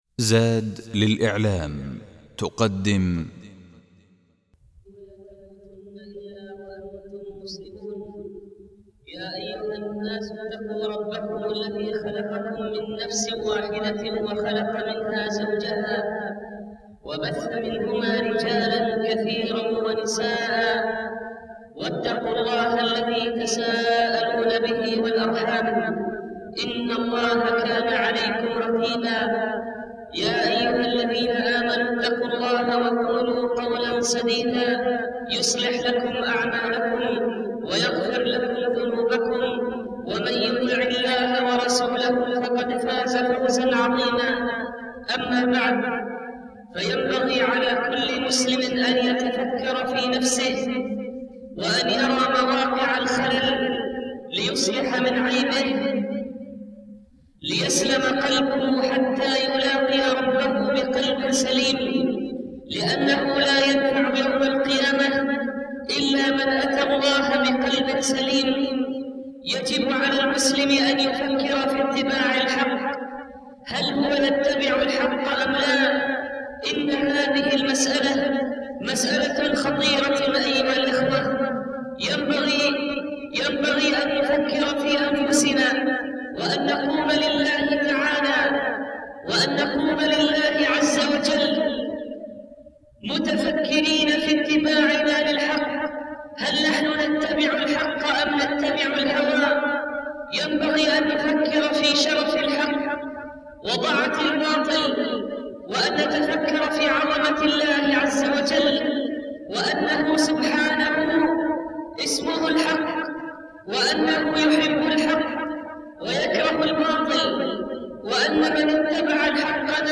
الخطبة الأولى